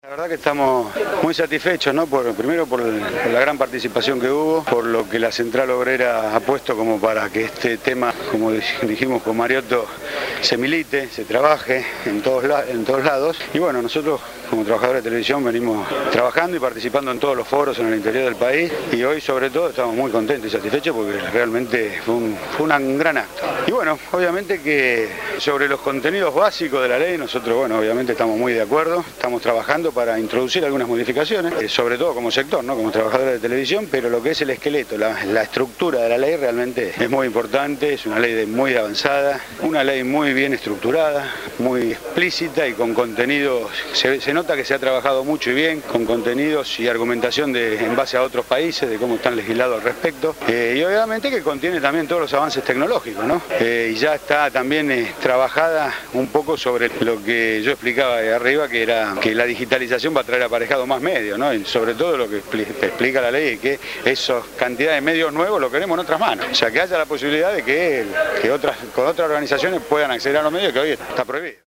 En el edificio de la CGT se llevó a cabo una jornada de apoyo a la Nueva Ley de Servicios Audivisuales que suplante la actual Ley de Radiodifusión de la dictadura militar, profundizada por Carlos Menem en 1992.
recogió audios de la charla-debate.